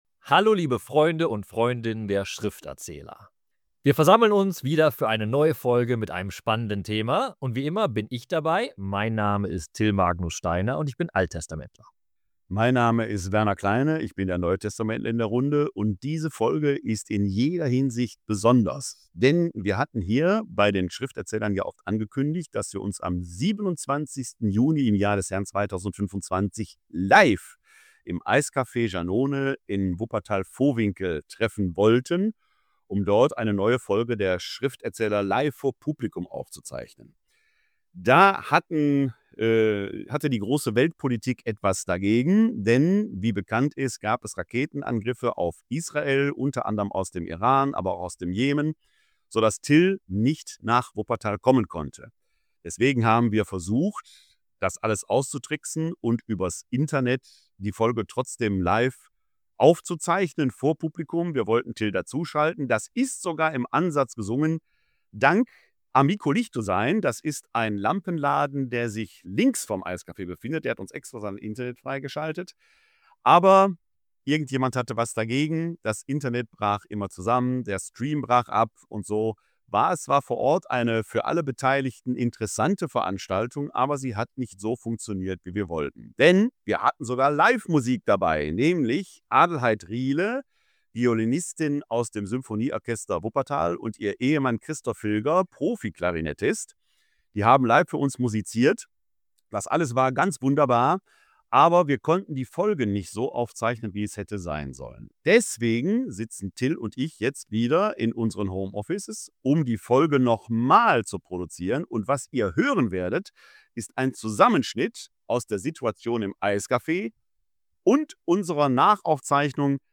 Auch das Internet machte an dem Tag der Aufzeichnung immer wieder Probleme. So ist diese Folge in zwei Sitzungen entstanden – im Eiscafé und später noch einmal im Studio. Ihr hört einen Mix aus beiden Aufzeichnungen.